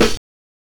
Snares
SNARE CONG 2.wav